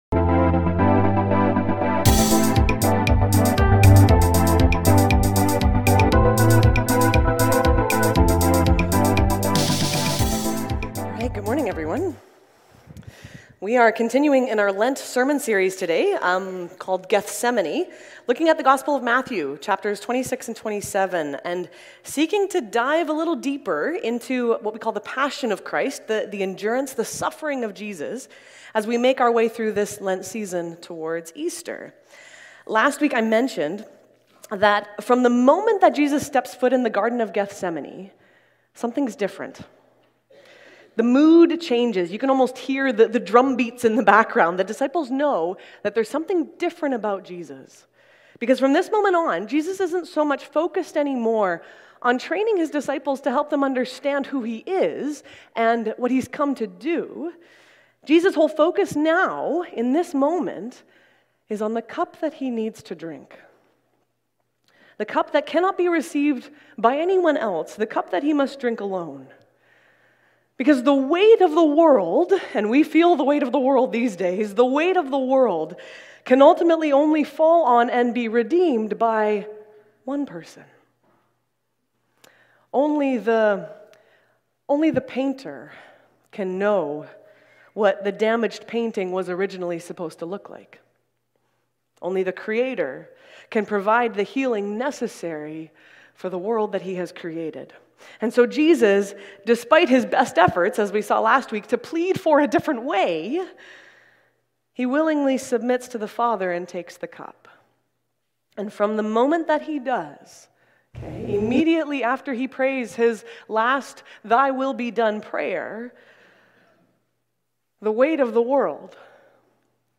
Willoughby Church Sermons | Willoughby Christian Reformed Church